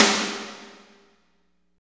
48.02 SNR.wav